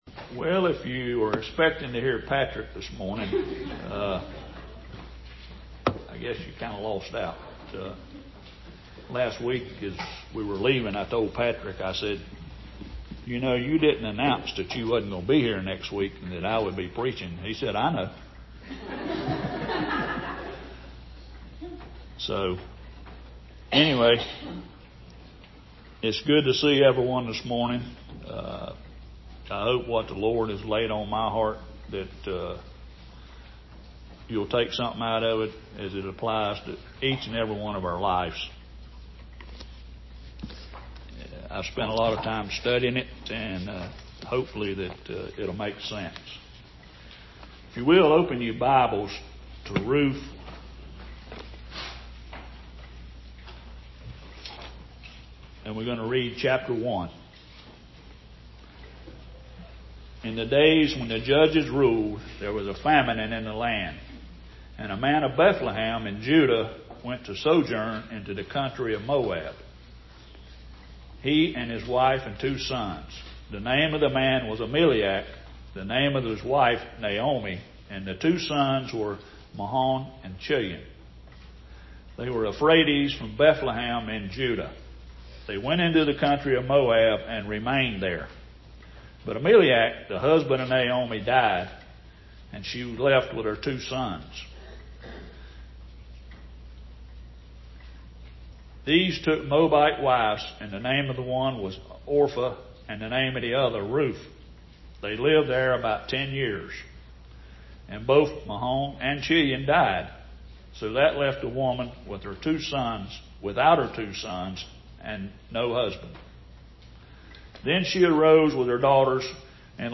Sermon on Ruth – Bethel Bear Creek Reformed Church: Media